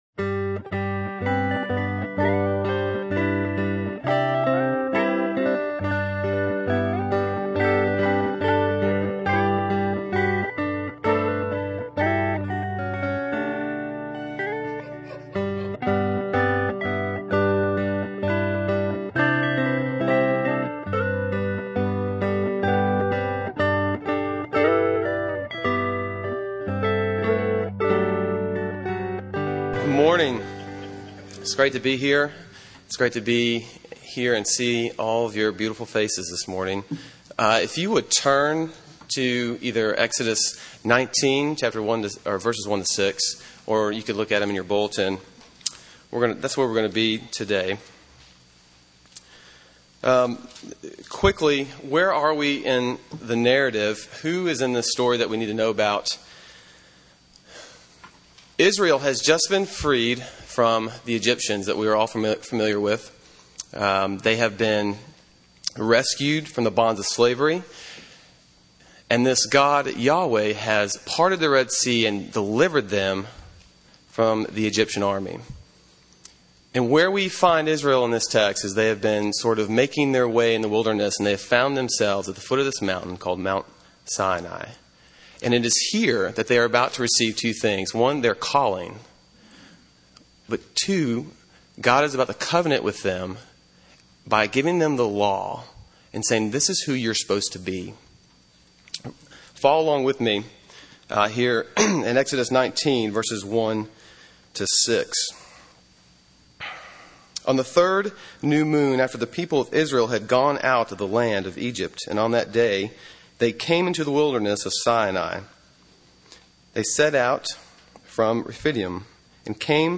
Sermon on Exodus 19:1-6 from November 16, 2008